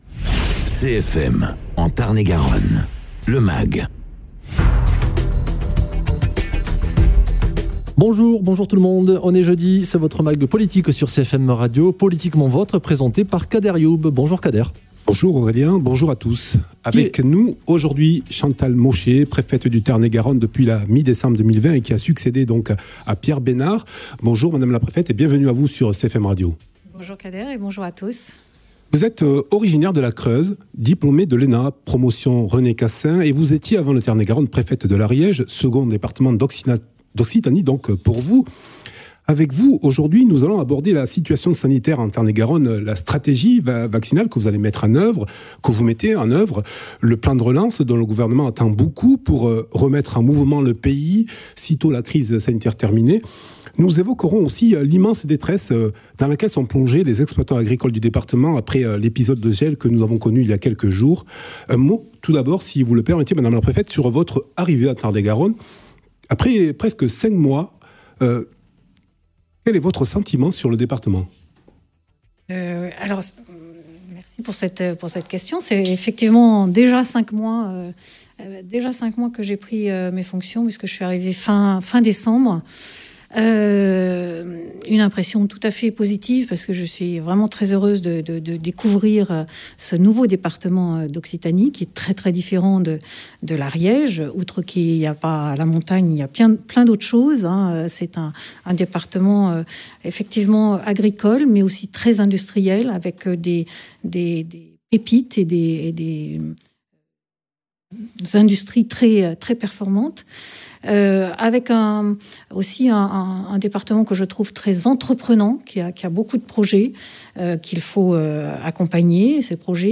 Invité(s) : Chantal Mauchet, préfète du Tarn-et-Garonne